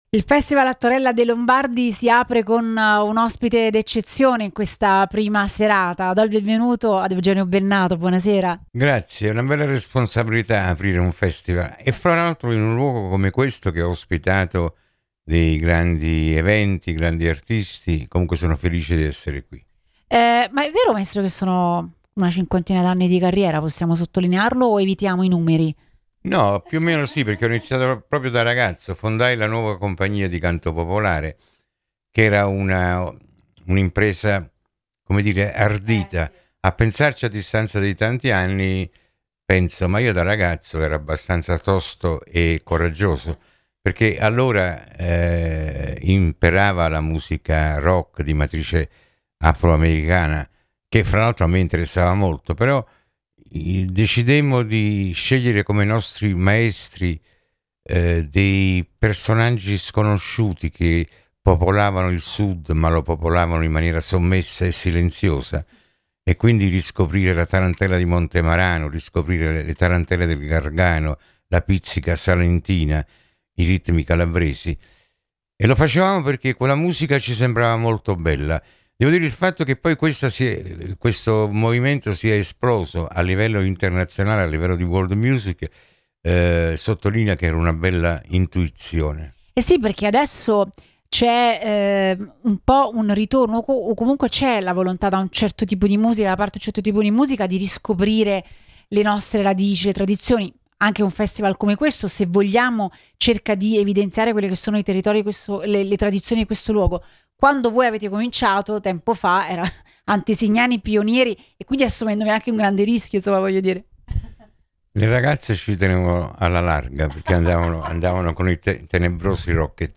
«È una bella responsabilità aprire un festival, soprattutto in un luogo come questo, che ha ospitato grandi eventi e artisti importanti. Ma sono felice di essere qui», ha dichiarato Bennato ai microfoni di Radio Punto Nuovo.